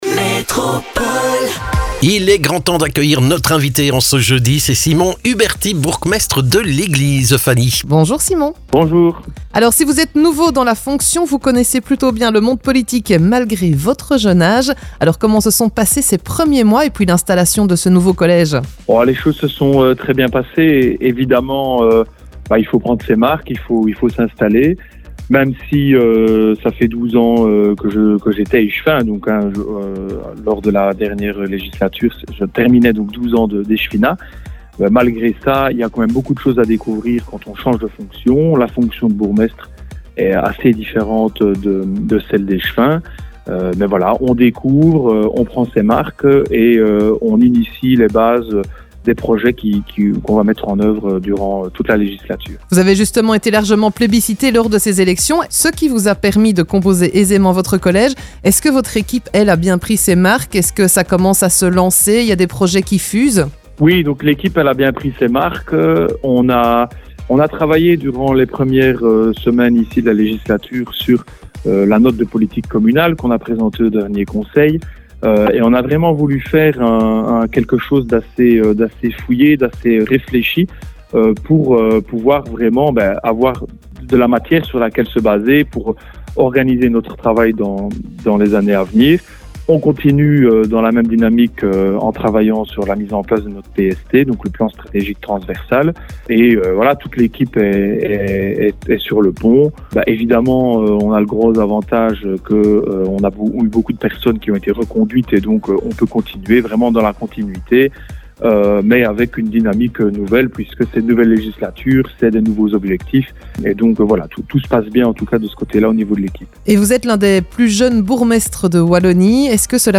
Simon Huberty se confie sur l'avenir de Léglise sur notre antenne.